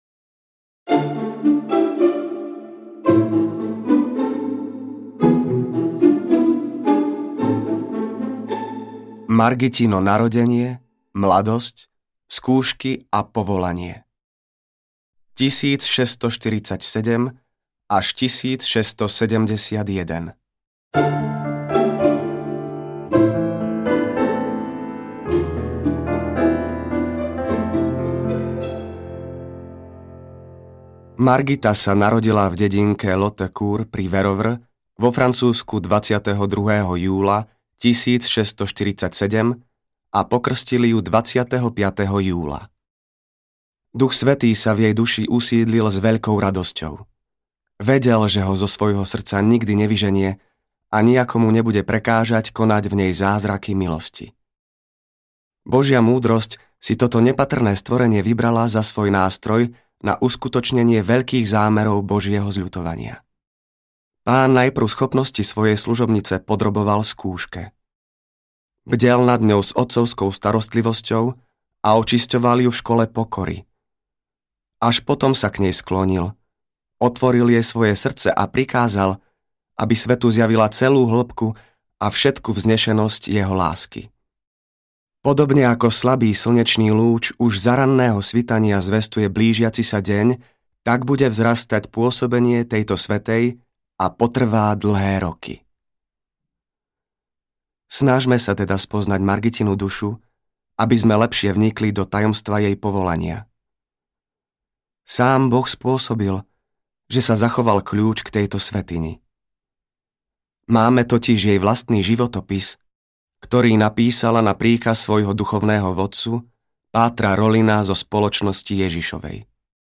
Margita Mária Alacoque audiokniha
Ukázka z knihy